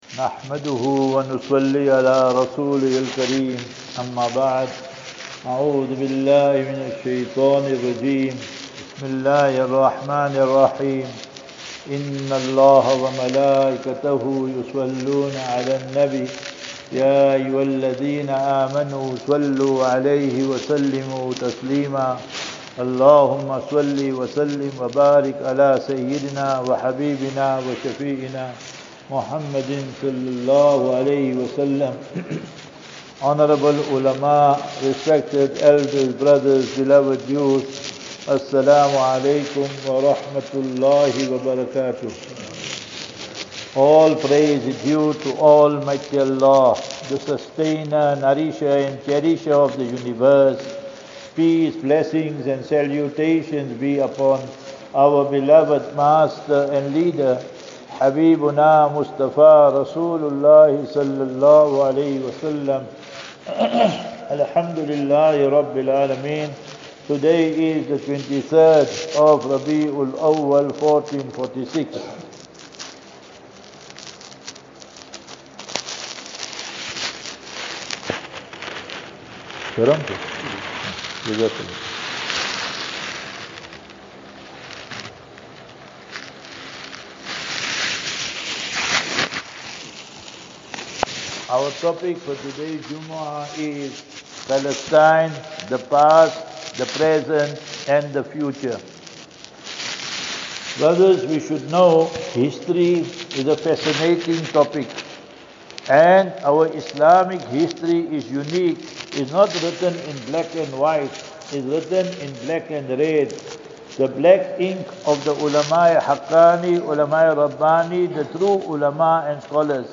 Jumah Lecture at Mbabane